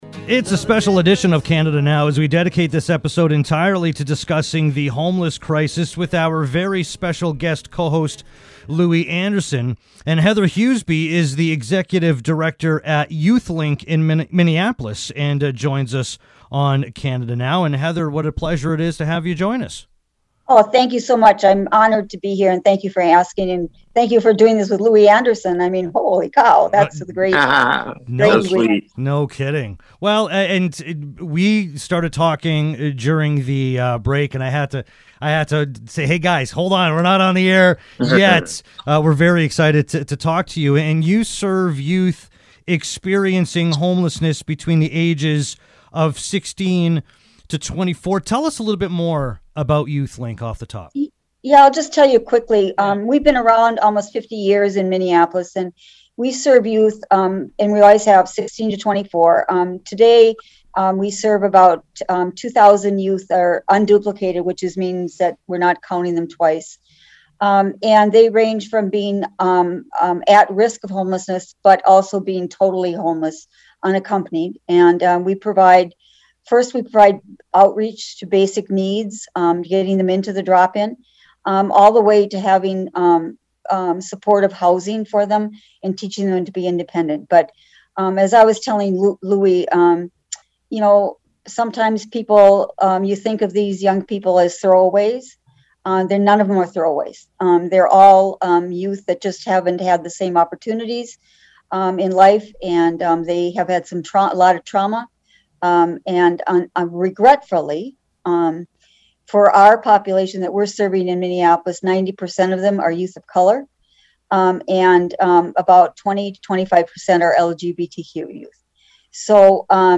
Click on the link below to listen to the interview! One of the many topics discussed is that YouthLink has an Amazon wish list where you can easily order items needed by youth experiencing homelessness and have them shipped directly to YouthLink.